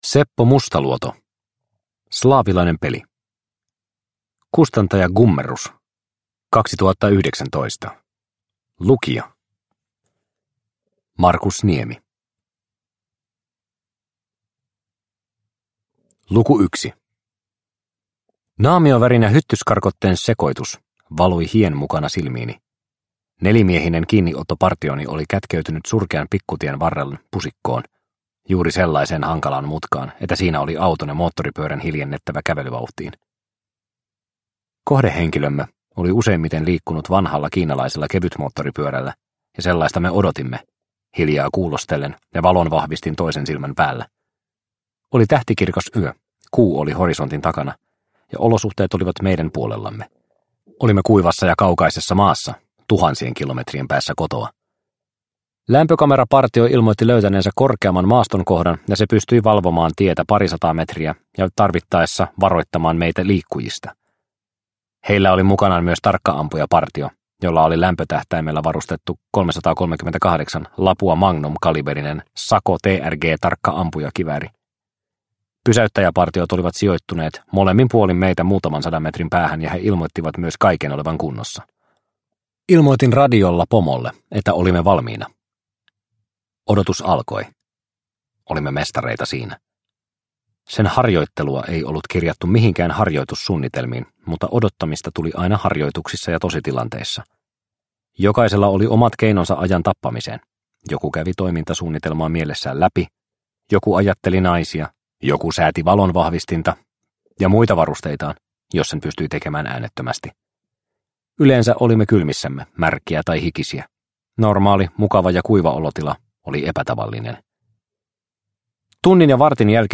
Slaavilainen peli – Ljudbok – Laddas ner